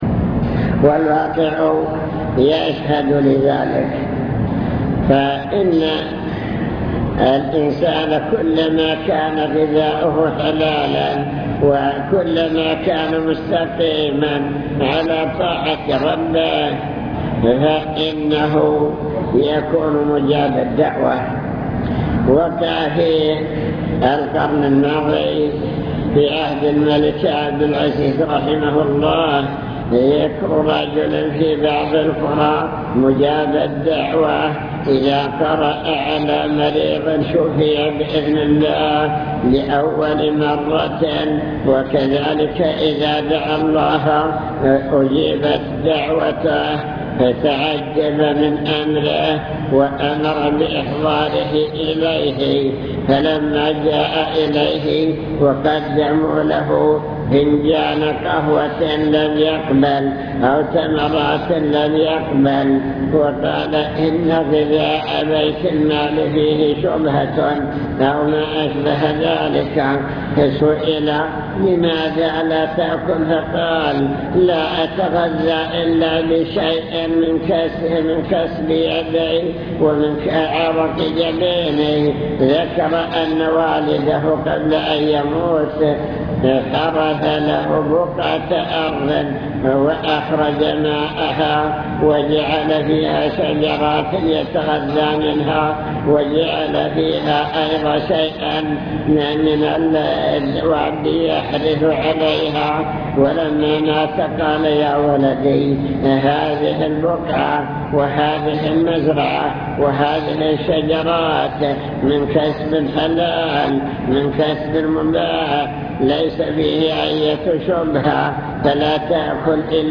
المكتبة الصوتية  تسجيلات - محاضرات ودروس  محاضرة بعنوان المكسب الحلال والمكسب الحرام